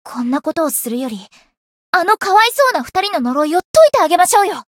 灵魂潮汐-神纳木弁天-七夕（送礼语音）.ogg